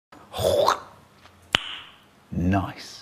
click-noice.mp3